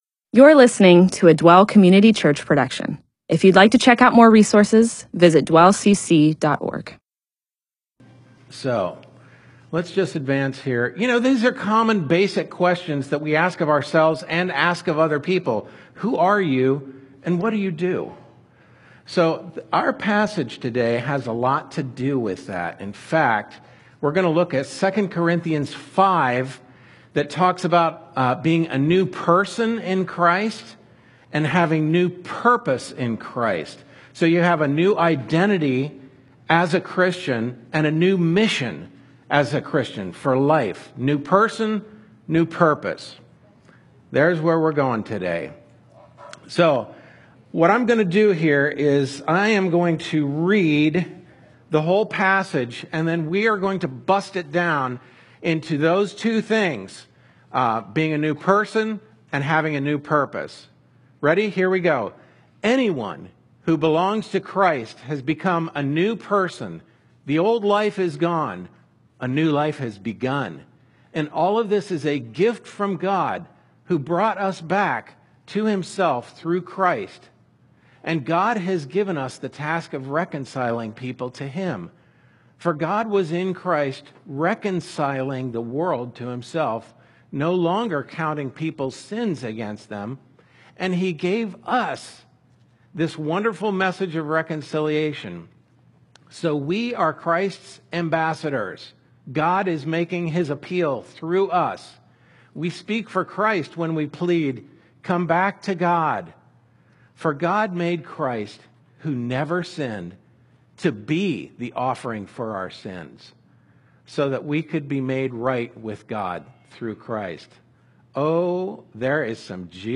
MP4/M4A audio recording of a Bible teaching/sermon/presentation about 2 Corinthians 5:17-21.